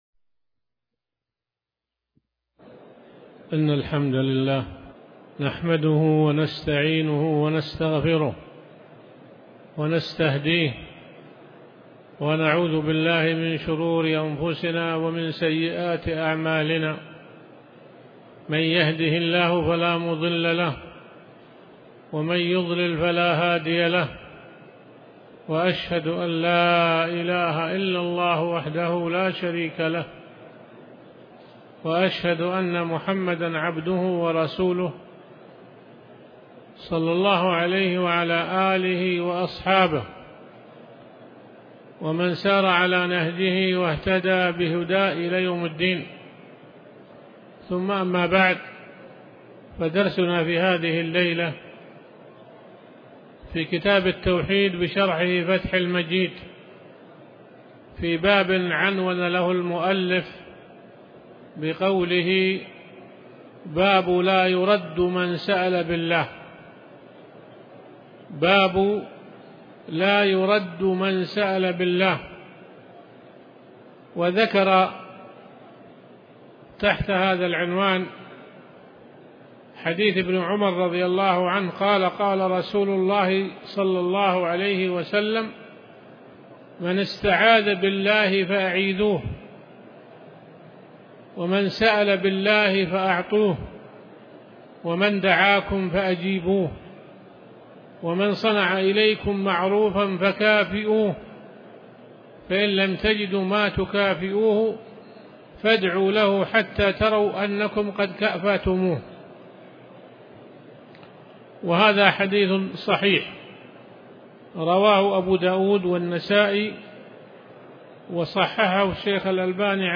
تاريخ النشر ١ شعبان ١٤٤٠ هـ المكان: المسجد الحرام الشيخ